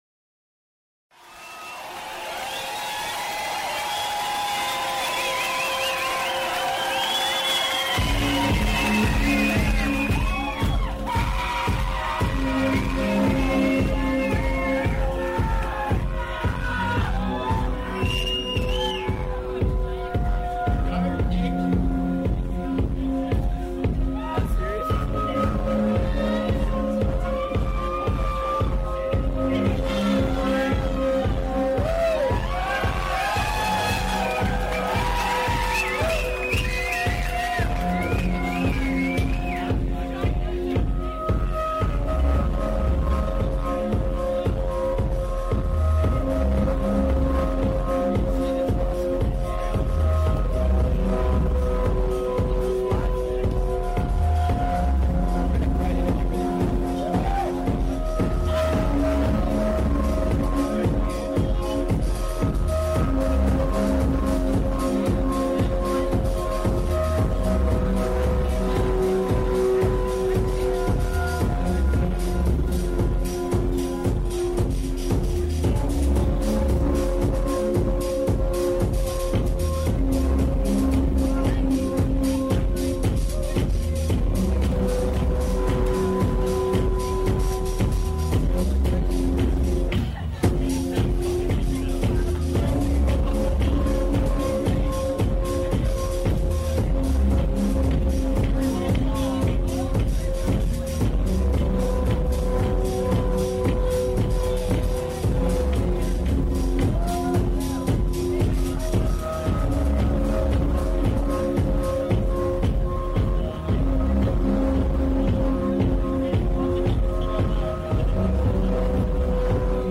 venue Prince Bandroom